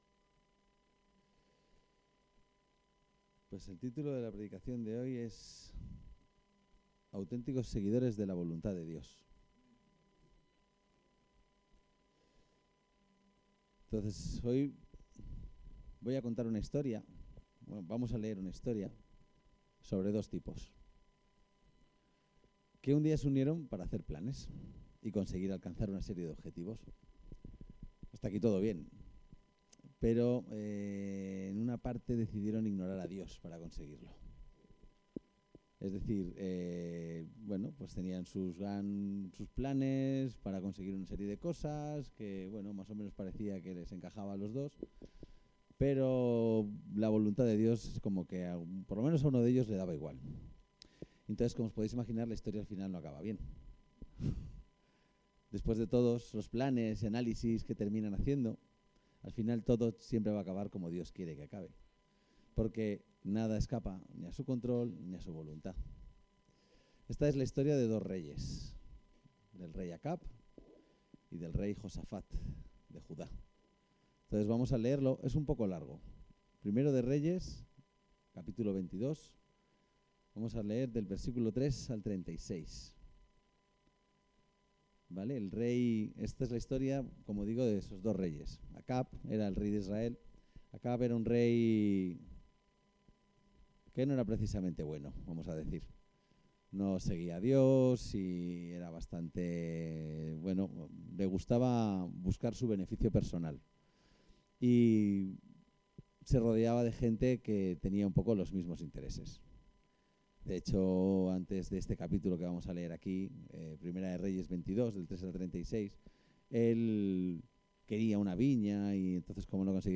El texto de la predicación se puede encontrar aquí Autenticos seguidores de la voluntad de Dios